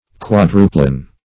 Search Result for " quadruplane" : The Collaborative International Dictionary of English v.0.48: Quadruplane \Quad"ru*plane\, n. [L. quadru- in comp.